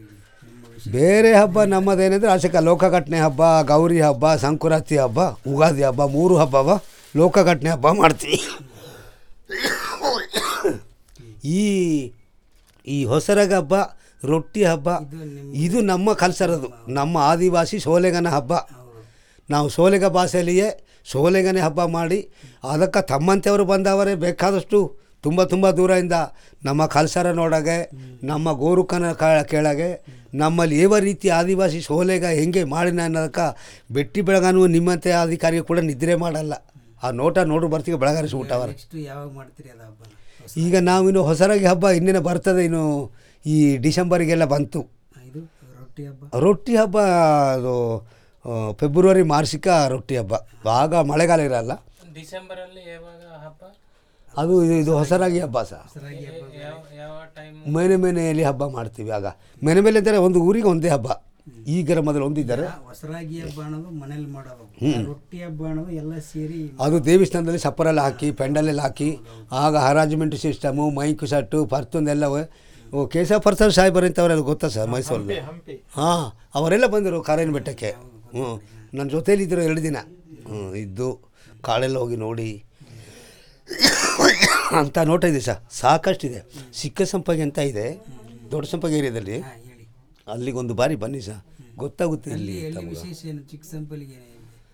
Traditional narrative about regarding Festivals